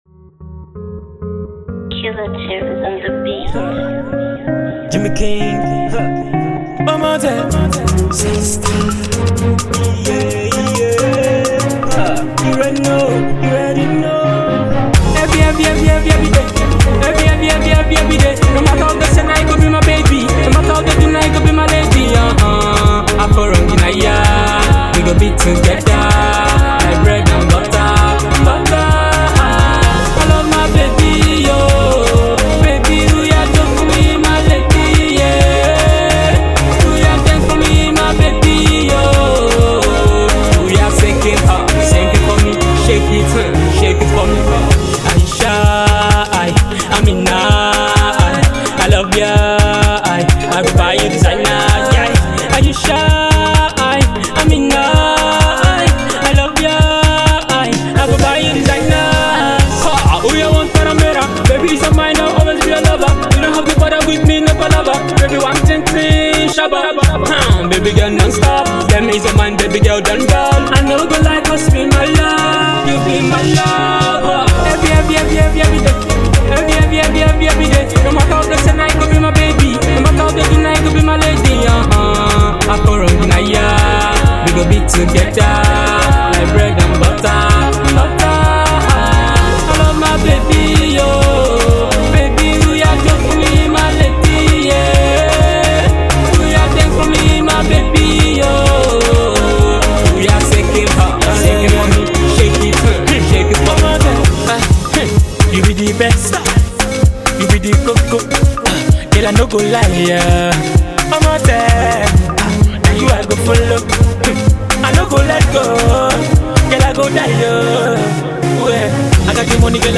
high life/afro pop single
love song with very rich lyrical content and groovy beat